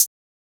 Closed Hats
HiHat 2.wav